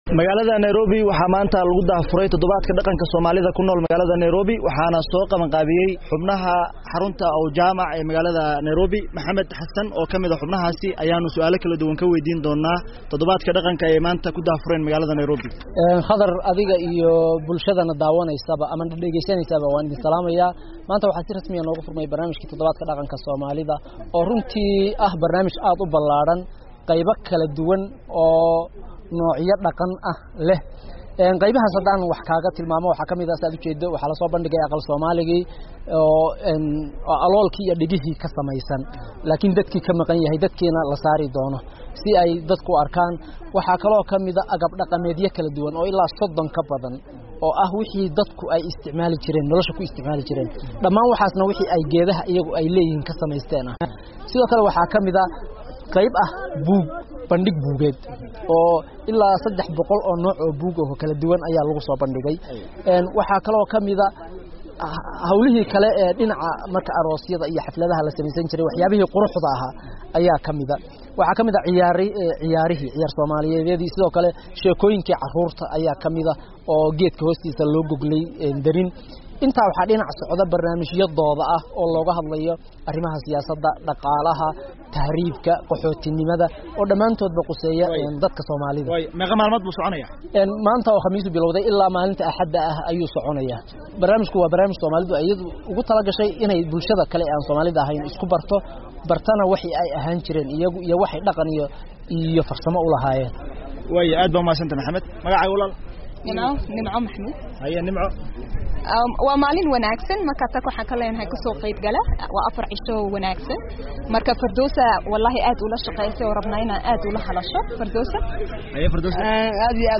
warbixintaan